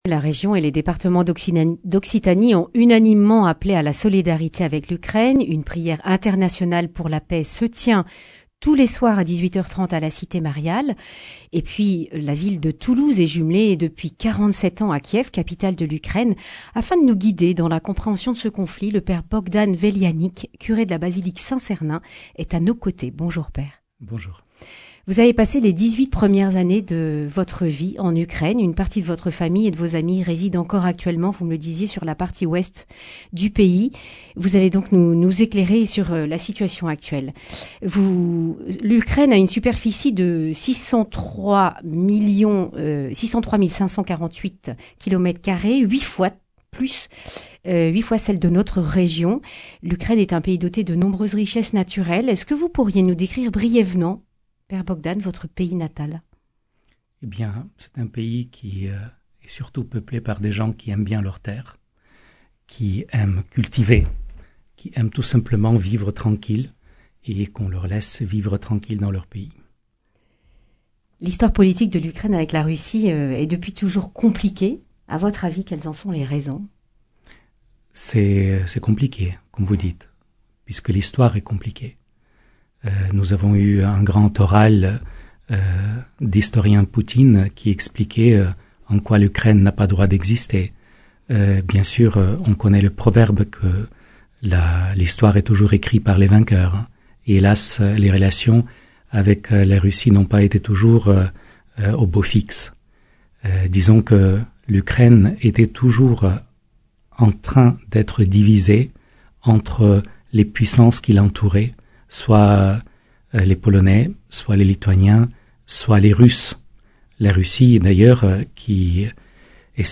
Accueil \ Emissions \ Information \ Régionale \ Le grand entretien \ Comment l’Ukraine est-elle arrivée à cette situation de guerre ?